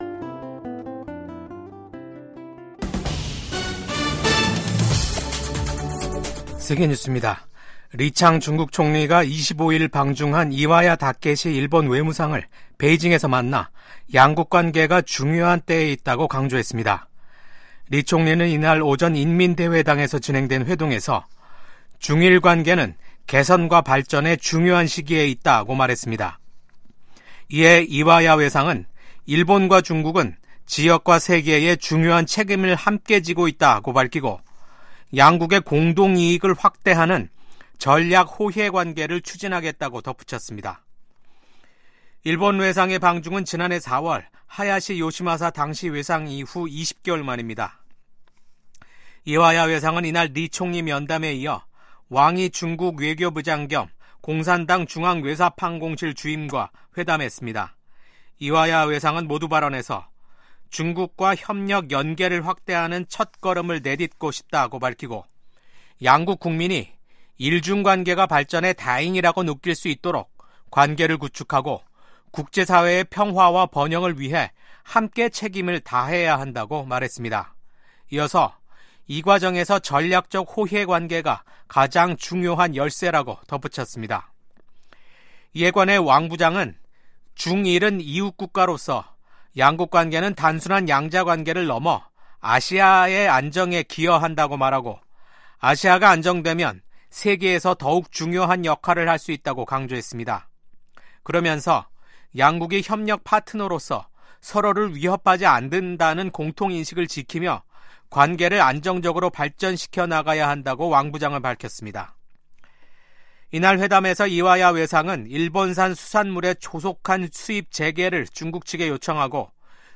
VOA 한국어 아침 뉴스 프로그램 '워싱턴 뉴스 광장'입니다. 한국의 계엄과 탄핵 사태로 윤석열 대통령이 미국과 공조해 추진해 온 미한일 3국 협력이 지속 가능하지 않을 수 있다고 미 의회조사국이 평가했습니다. 미국과 한국 정부가 한국의 비상계엄 사태로 중단됐던 양국의 외교안보 공조 활동을 재개키로 했습니다. 일본에서 발생한 대규모 비트코인 도난 사건이 북한 해커집단의 소행으로 밝혀졌습니다.